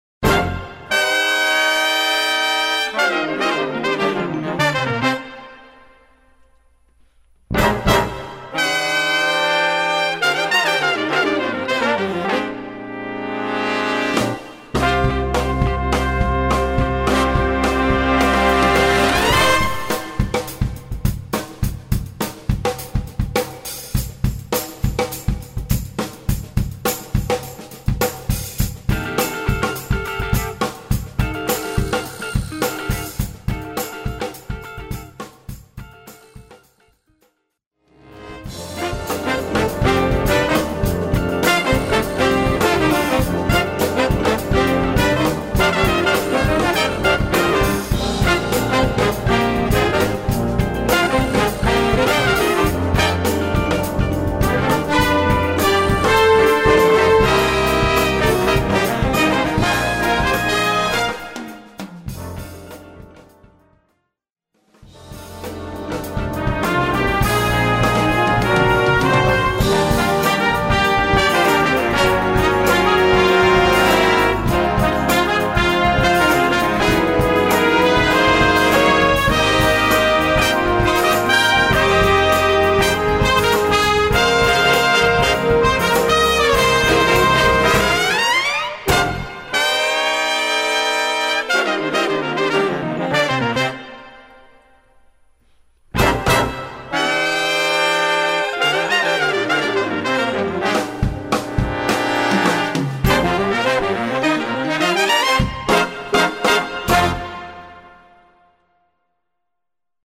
Gattung: Solo für Horn und Blasorchester
Besetzung: Blasorchester